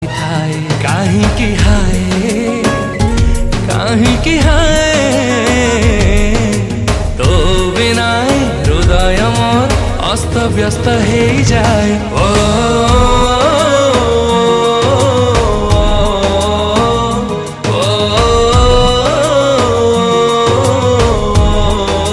Odia Ringtones
dance song